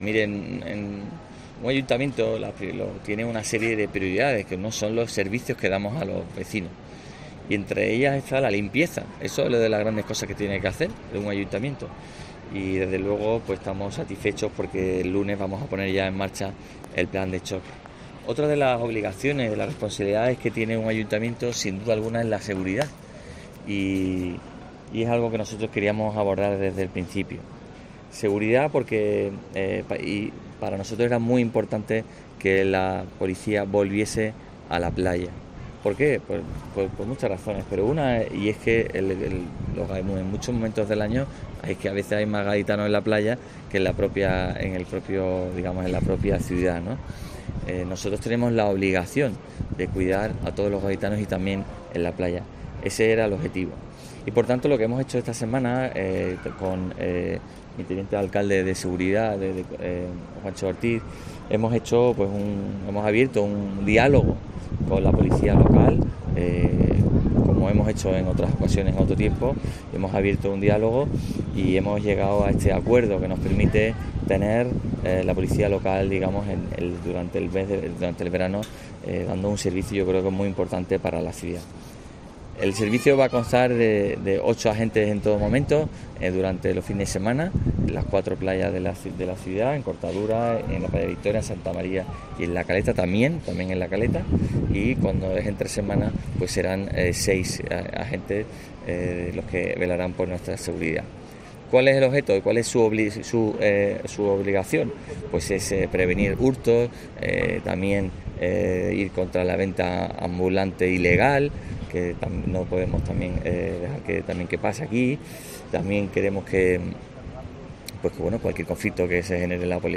El alcalde de Cádiz, Bruno García, habla de la vuelta de la Policía Local a las playas